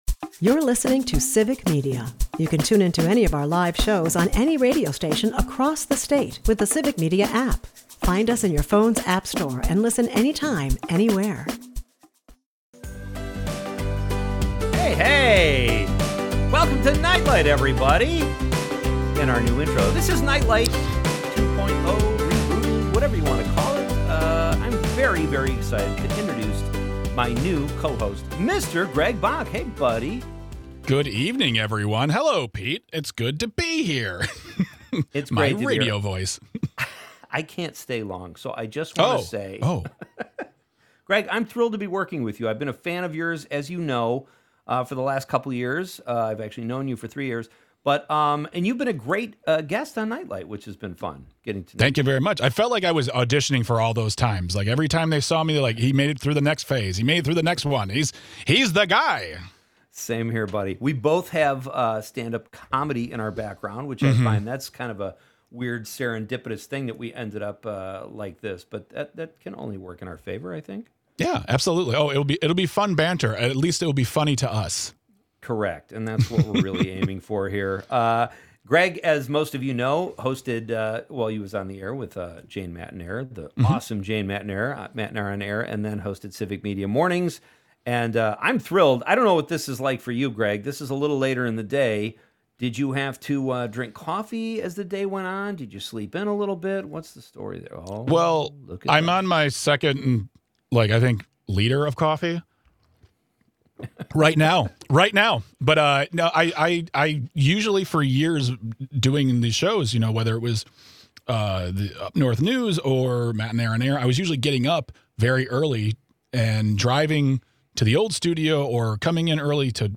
With sharp banter, a few strong opinions, and plenty of laughs, Nite Lite 2.0 feels fresh, fast, and ready for whatever comes next.